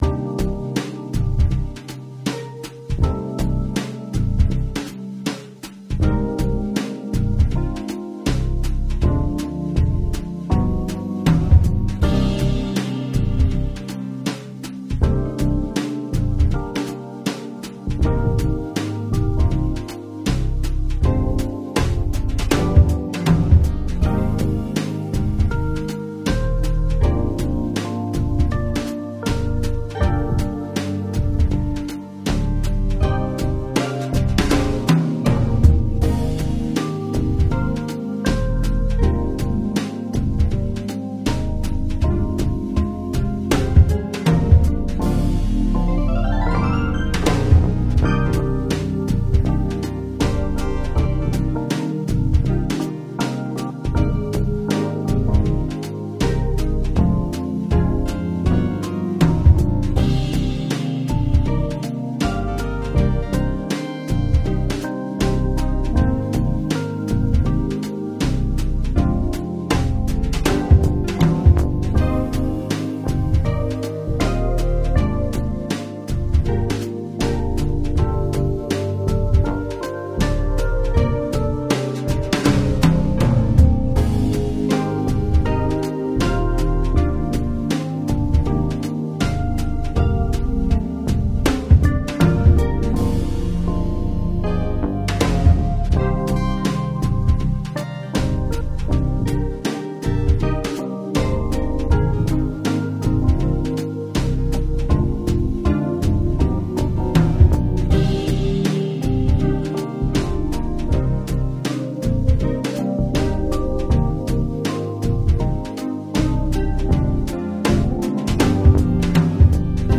Microwave synth jazz chili track to solo over (16 bar loop)
It’s a 16 bar jazz progression that repeats.
Used 4 instances of Microwave in AUM and recorded with MidiTapeRecorder. Drums is a 16 bar SessionBand track I saved as a wav and loaded/looped in AUM.
Bass is ‘Moog bass 2’. Other presets are ‘vollenweider’, ‘spacedodessy,’, ‘formantchord’.